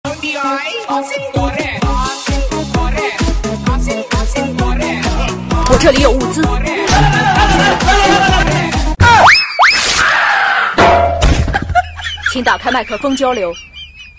SFX刺激战场吃鸡搞笑音效下载
SFX音效